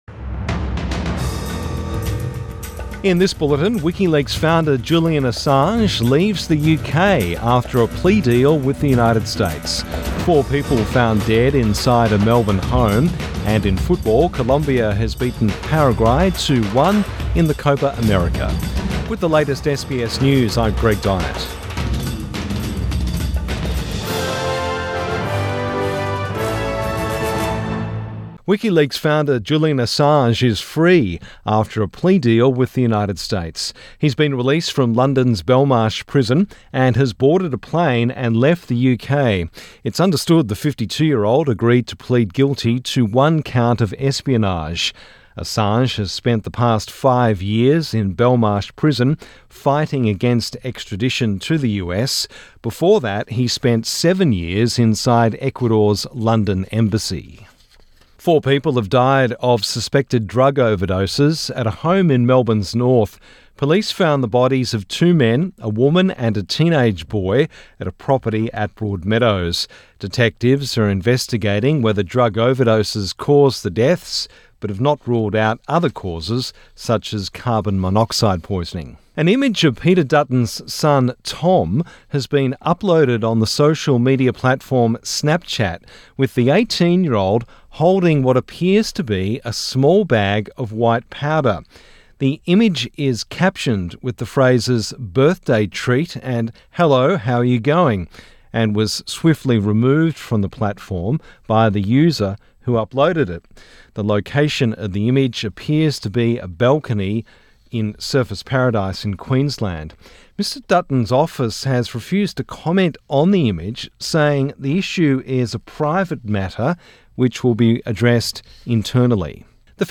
Midday News Bulletin 25 June 2024